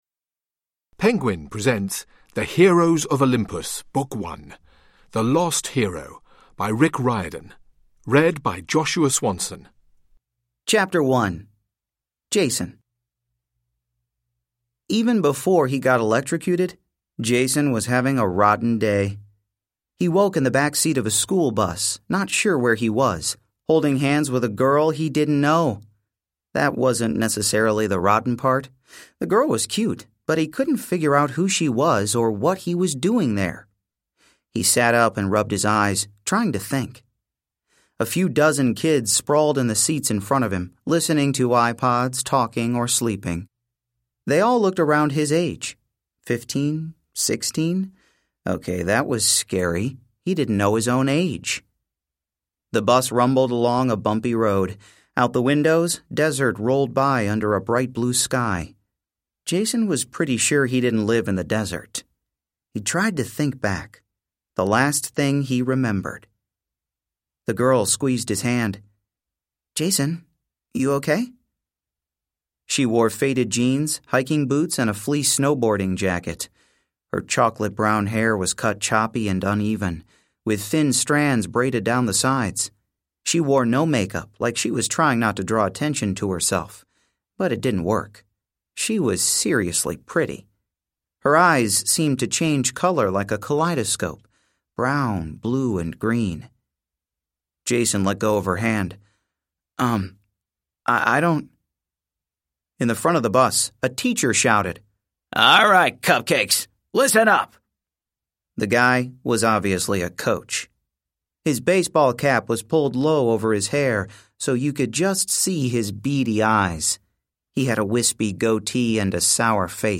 Penguin presents the unabridged, downloadable audiobook edition of the The Lost Hero, book one in the Heroes of Olympus spin-off series from Percy Jackson creator, Rick Riordan.